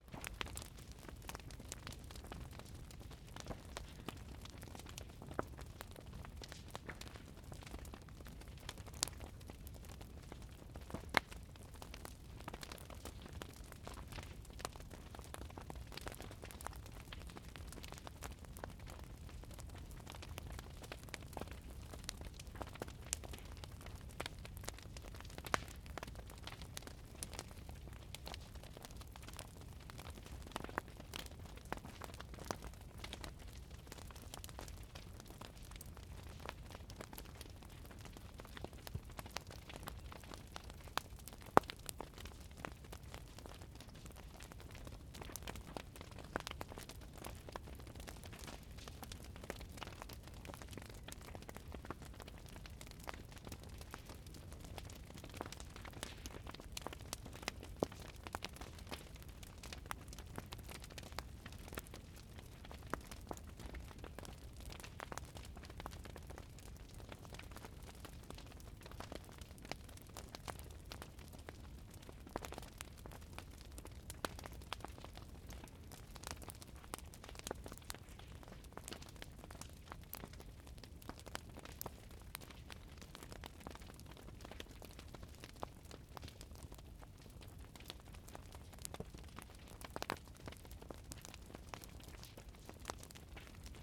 Burning_House_t4_Fire_low_intensity_with_crackling_MKH8060.ogg